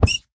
hurt2.ogg